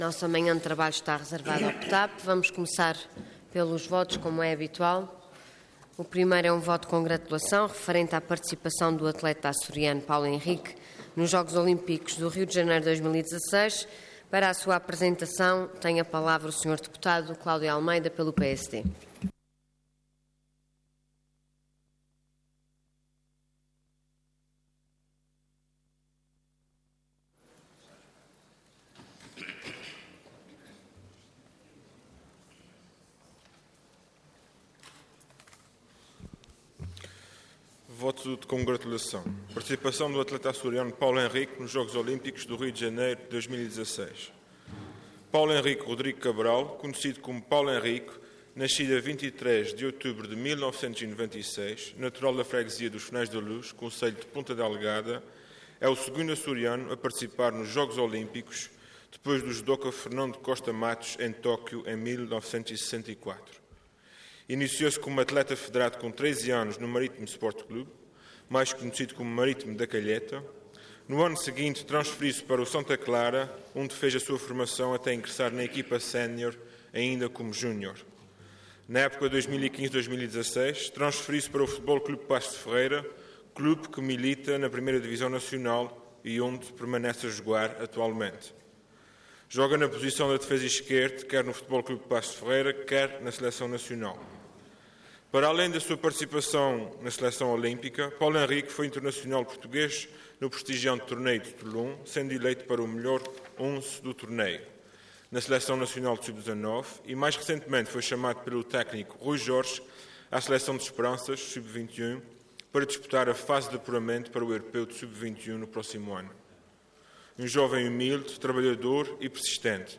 Website da Assembleia Legislativa da Região Autónoma dos Açores
Intervenção Voto de Congratulação Orador Cláudio Almeida Cargo Deputado Entidade PSD